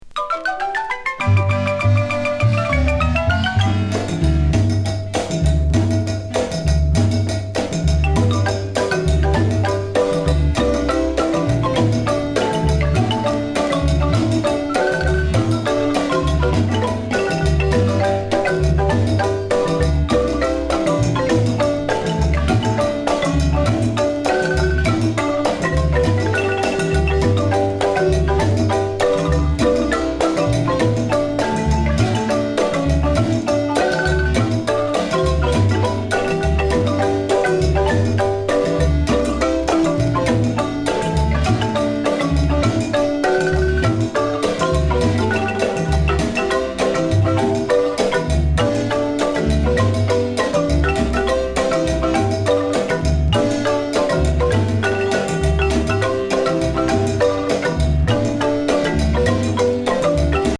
ライブラリー・エアロビ用レコード